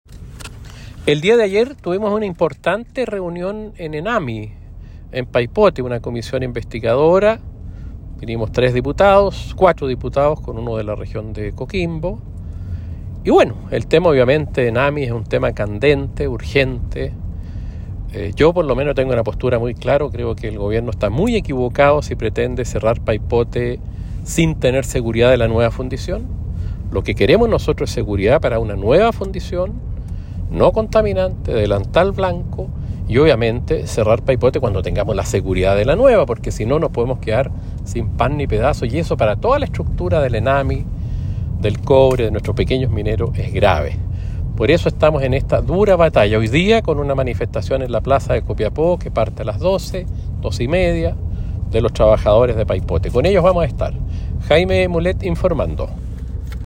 Audio Diputado Jaime Mulet, donde se refiere a la comisión realizada ayer en la fundición en Paipote y sobre la manifestación por cierre de la planta.